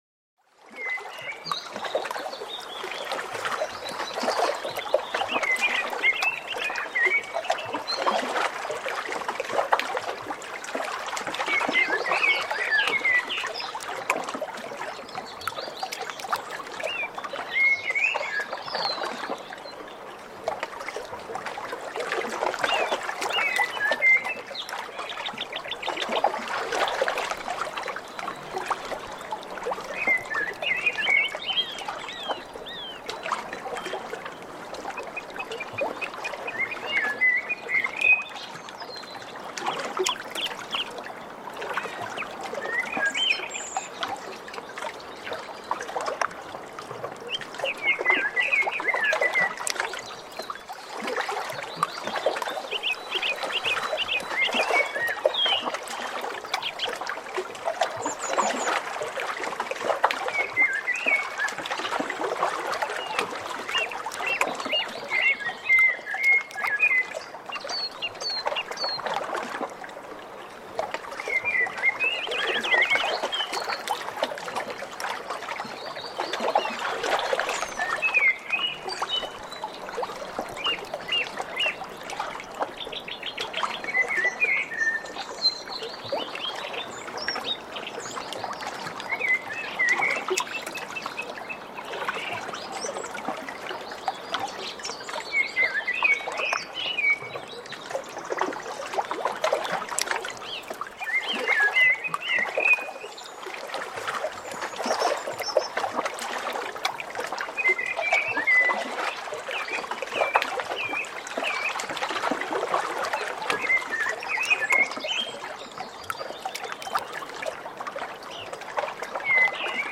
PERFEKTE ENTSPANNUNG: Ozeanflöte-Harmonie vereint Wind + Wasser